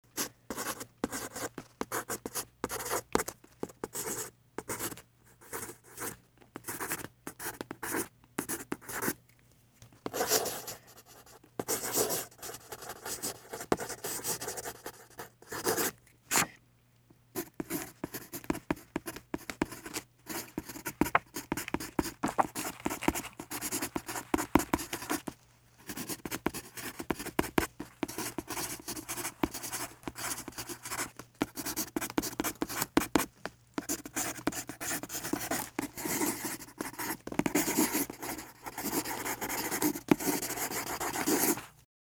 紙に書く鉛筆: